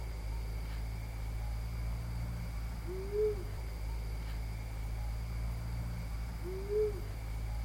Lechuzón Orejudo (Asio clamator)
Sobre unos eucaliptos bien grandes (Rivadavia y Moreno) vocalizaba esta lechuza..... no pude verla, cuando me acerque y reproduje su mismo canto enmudeció y no canto mas en toda la noche, por como se escuchaba y a la altura que estaba deduzco que era grande.
Identificada por varios amigos como Asio clamator, una voz un tanto atípica.
Nombre en inglés: Striped Owl
Localidad o área protegida: Parque Costero del Sur
Certeza: Vocalización Grabada